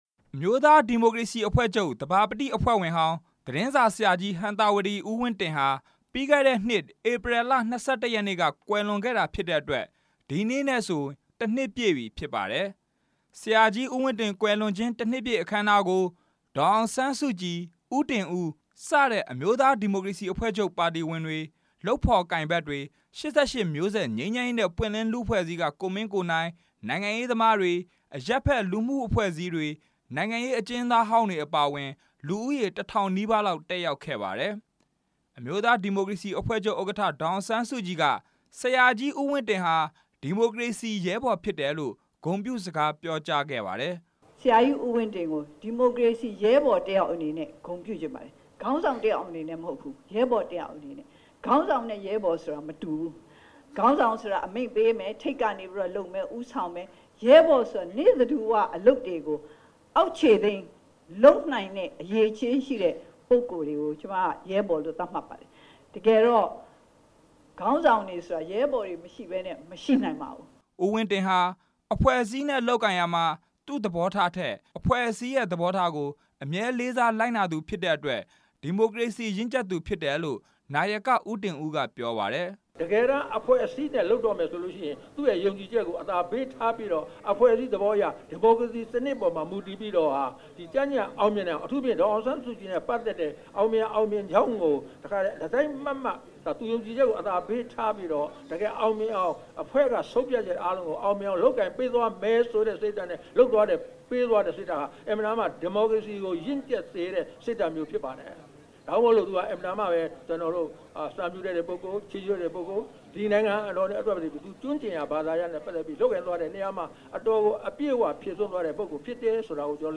ရန်ကုန်မြို့ တော်ဝင်နှင်းဆီခန်းမမှာ ဒီကနေ့ကျင်းပတဲ့ ဟံသာဝတီ ဦးဝင်းတင် ကွယ်လွန်ခြင်း ၁ နှစ်ပြည့် အောက်မေ့ဖွယ်အခမ်းအနားမှာ ဒေါ်အောင် ဆန်းစုကြည်က အခုလို ပြောကြားခဲ့တာပါ။